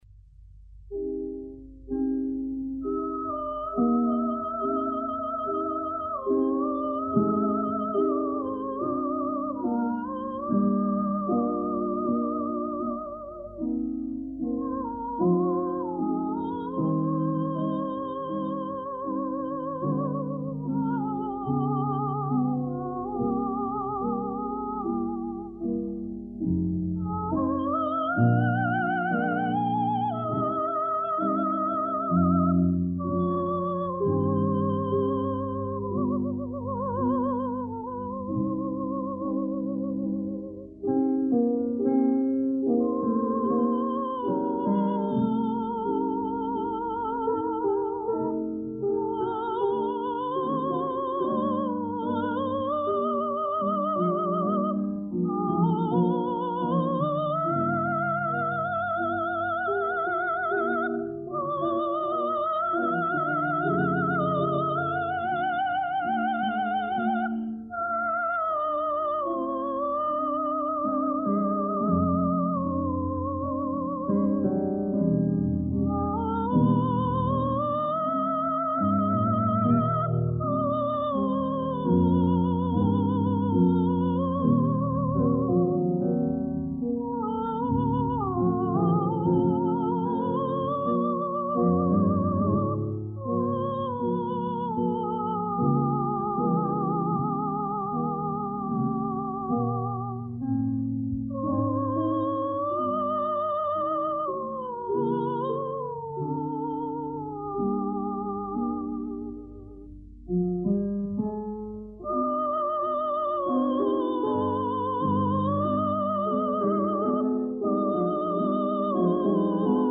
13rakhmaninov_vokaliz_solo_soprano.mp3